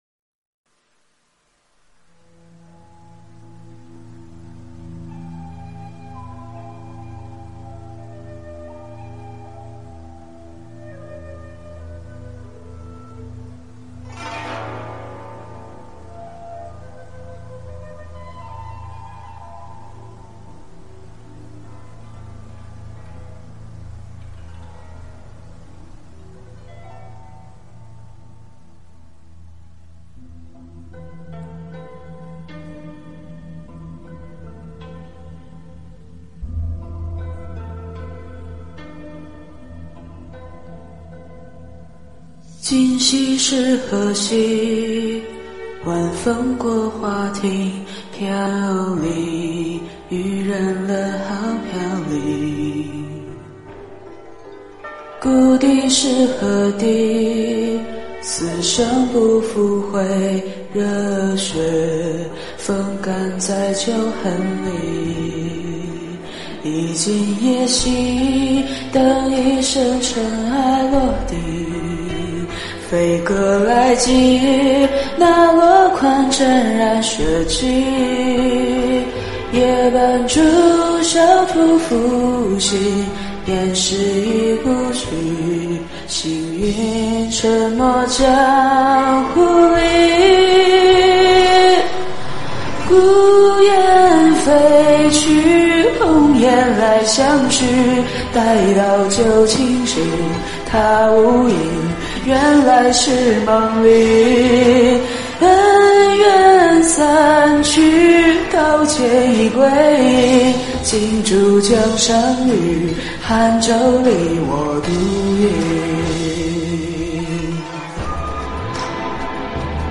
歌曲